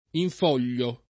in foglio [ in f 0 l’l’o ] → in folio